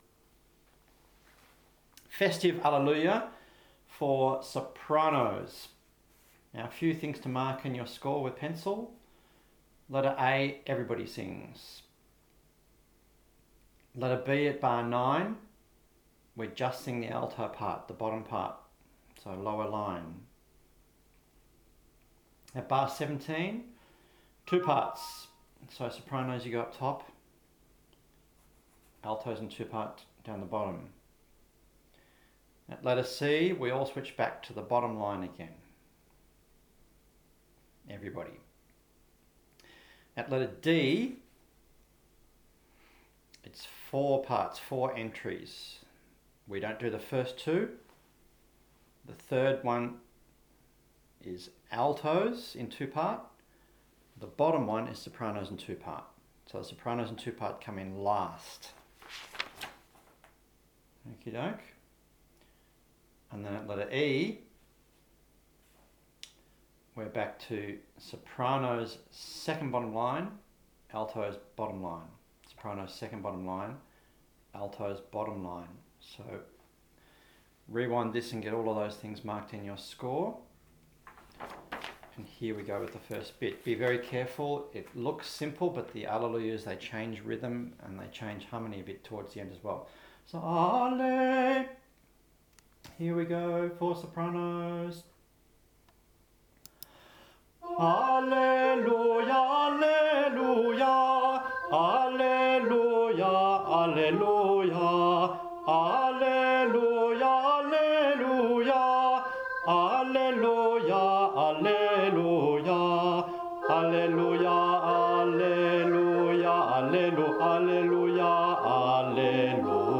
06 Festive Hallelujah – Soprano In 2